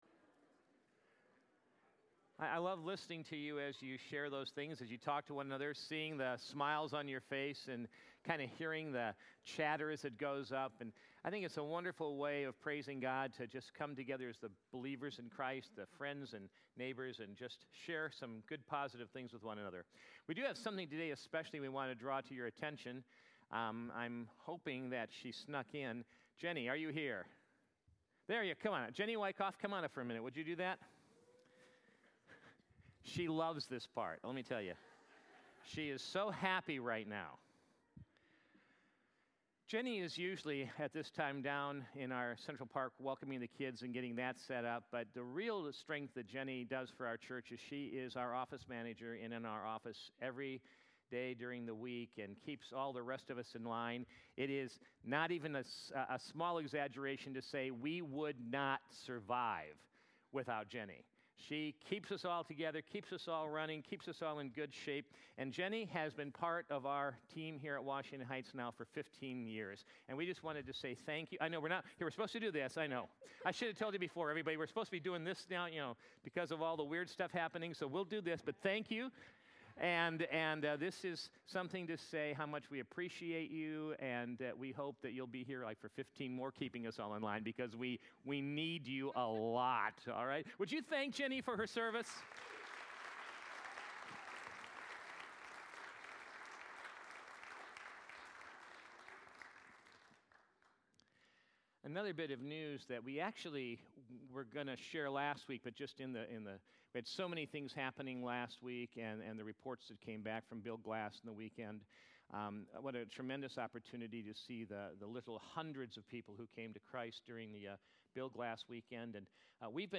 Sunday Morning Message
AM_Sermon_3_8_20.mp3